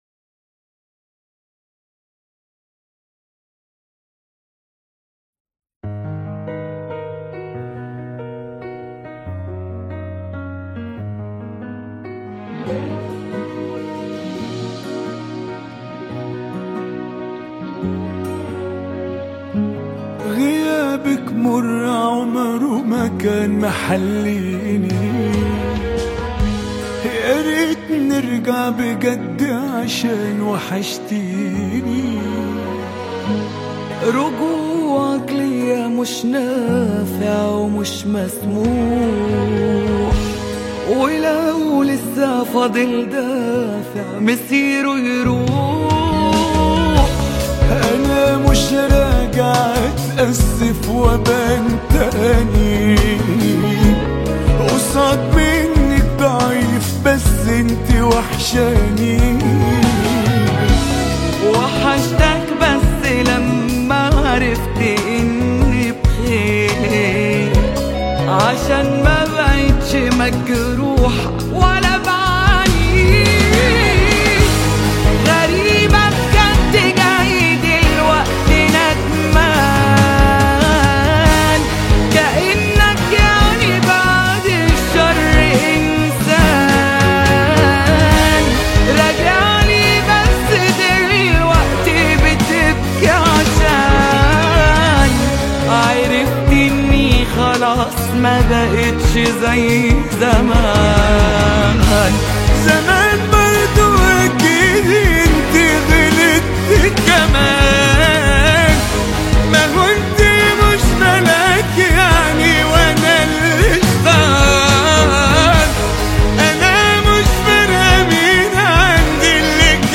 • اللون الغنائي: درامي / رومانسي حزين.